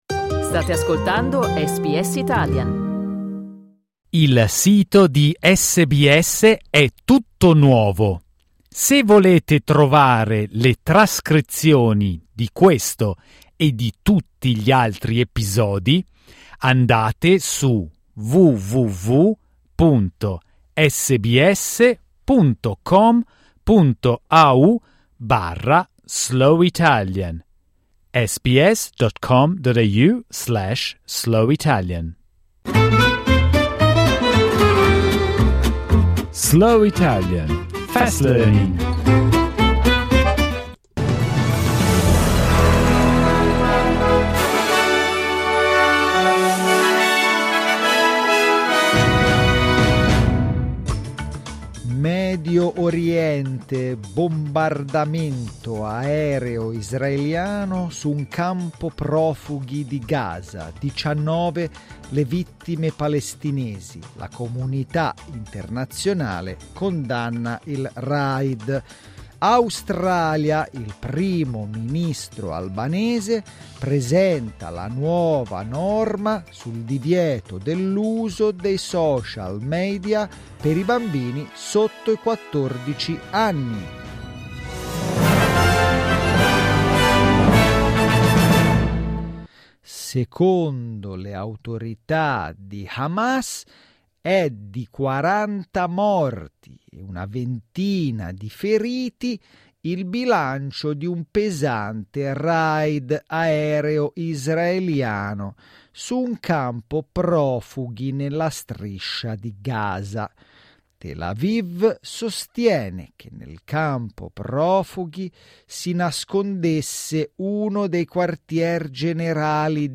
SBS Italian News bulletin, read slowly.